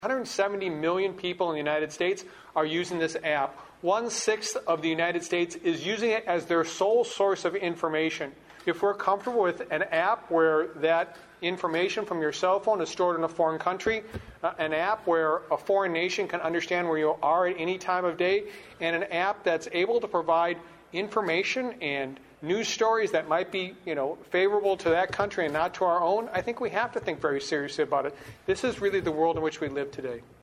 RETIRED GENERAL PAUL NAKASONE ENLIGHTENED THE CROWD AT THE SIOUXLAND CHAMBER’S ANNUAL DINNER THIS WEEK ON HOW DIGITAL TRANSFORMATION IS AFFECTING OUR COUNTRY.